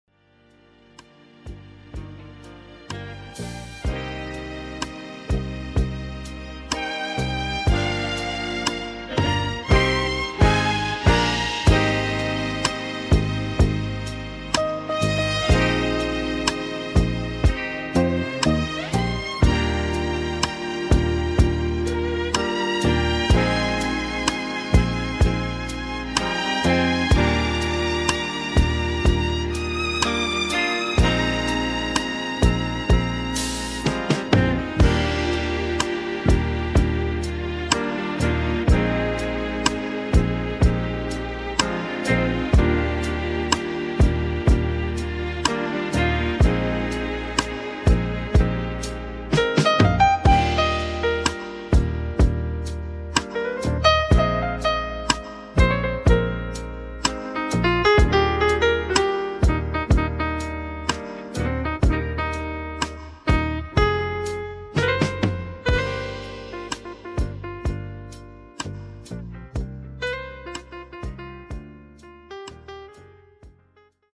Романтическая Музыка